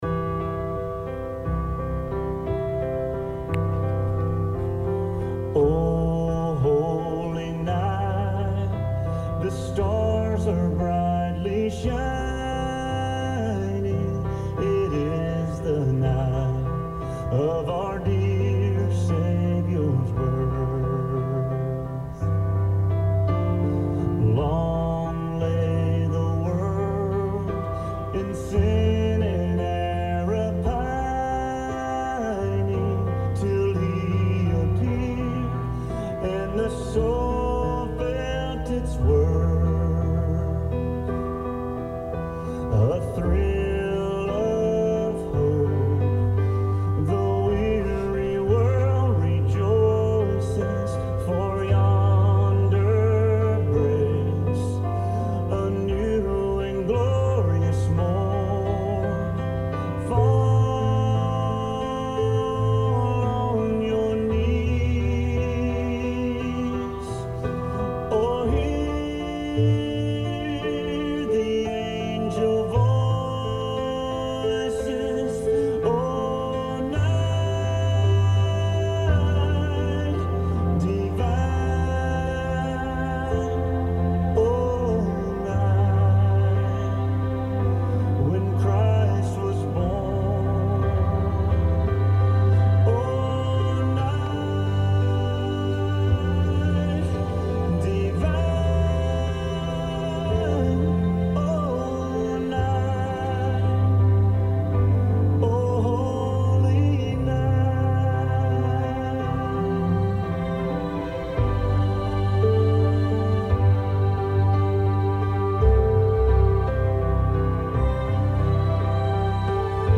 Country Christmas